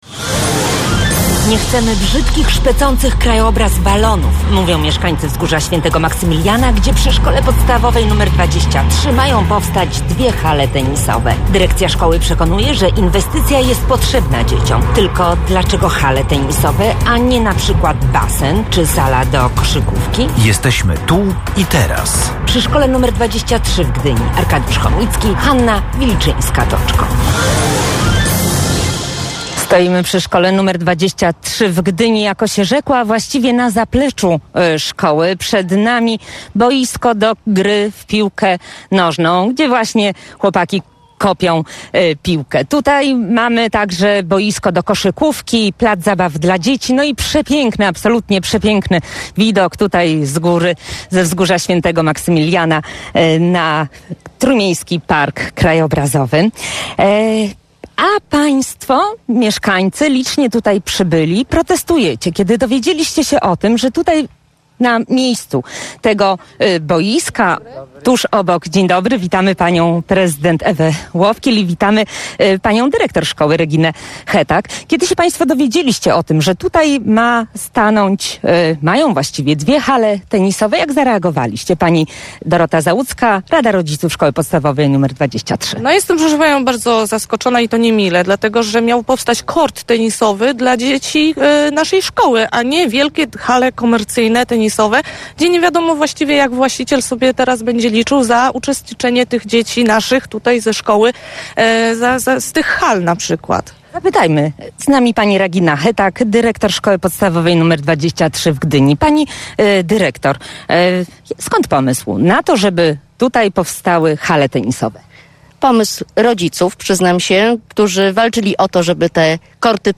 Ktoś tutaj chce nieźle zarobić, mówią rozgoryczeni mieszkańcy.